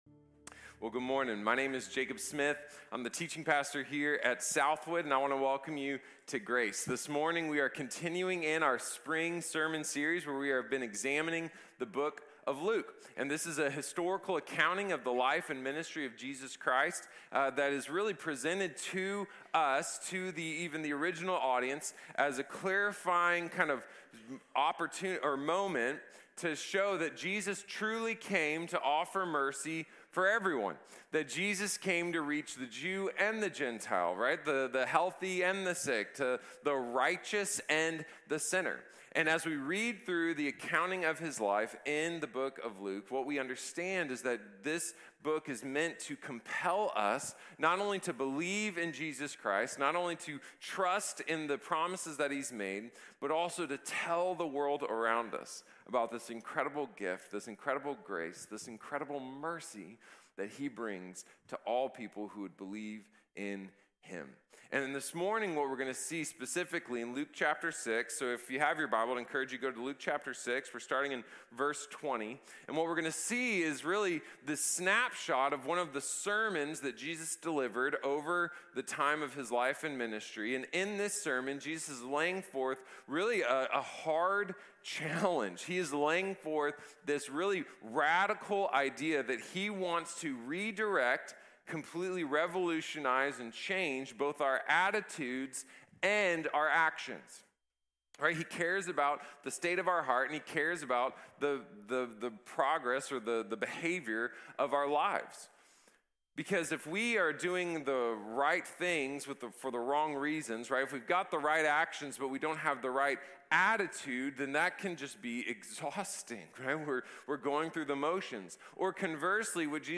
Radical Redirection | Sermon | Grace Bible Church